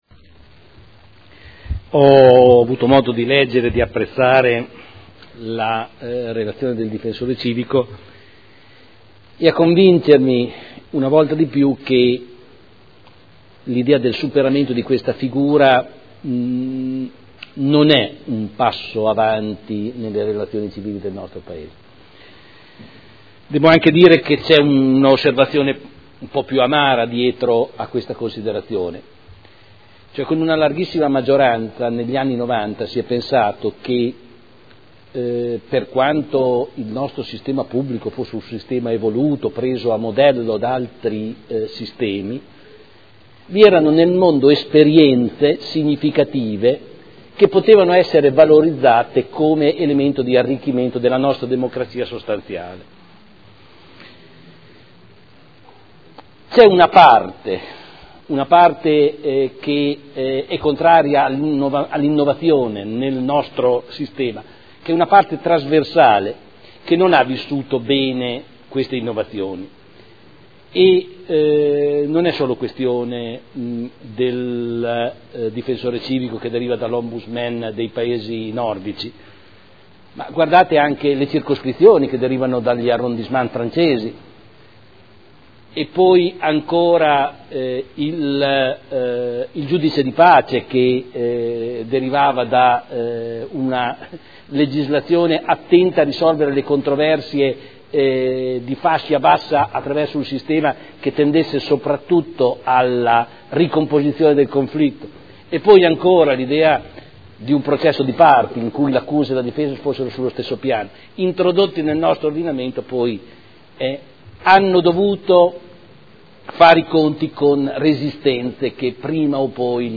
Seduta del 05/03/2012. Dibattito su relazione del Difensore Civico al Consiglio Comunale sull'attività svolta nell'anno 2011